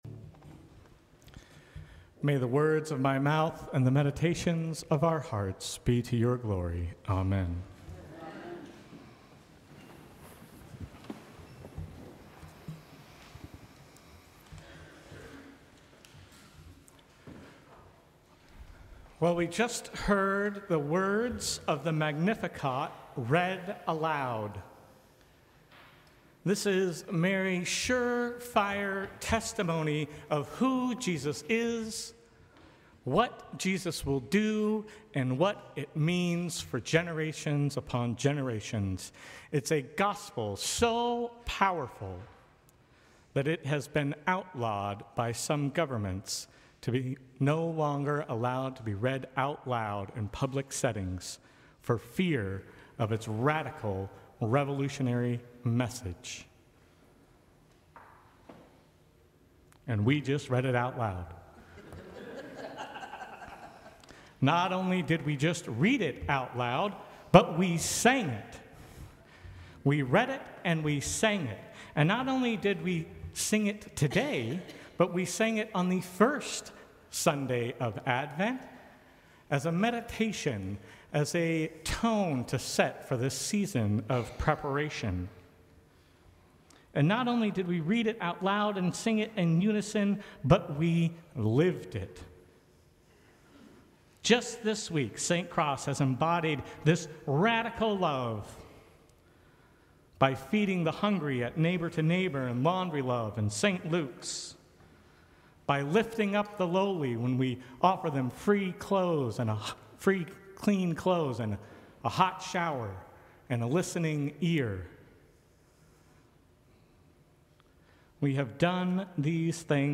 Sermons from St. Cross Episcopal Church Fourth Sunday of Advent Dec 22 2024 | 00:14:12 1x 00:00 / 00:14:12 Subscribe Share Apple Podcasts Spotify Overcast RSS Feed Share Link Embed